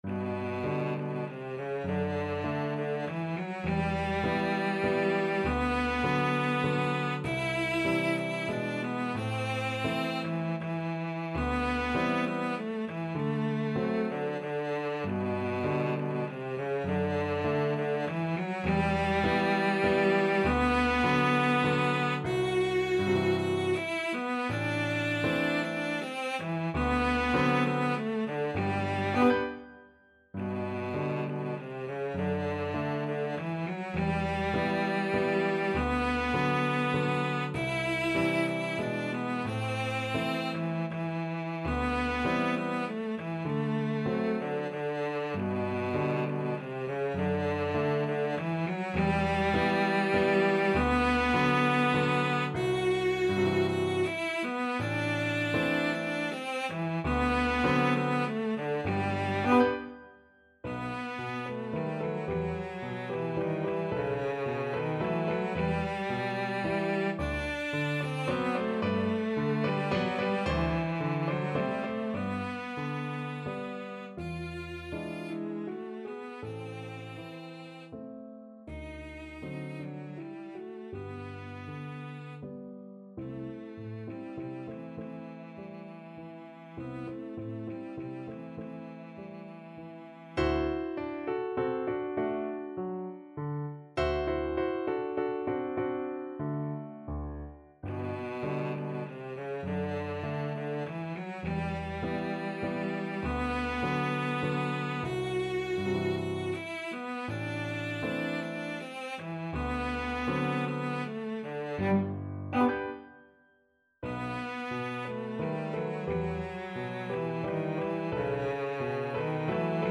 Classical Merikanto, Oskar Valse lente, Op.33 Cello version
3/4 (View more 3/4 Music)
G major (Sounding Pitch) (View more G major Music for Cello )
~ = 100 Tranquillamente
Cello  (View more Intermediate Cello Music)
Classical (View more Classical Cello Music)